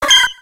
Cri de Germignon dans Pokémon X et Y.